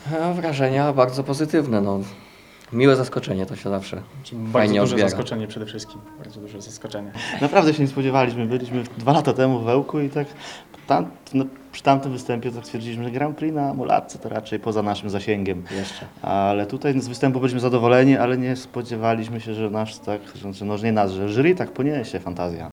W rozmowie z reporterem Radia 5 zwycięzcy nie kryli, że było to dla nich wielkie zaskoczenie.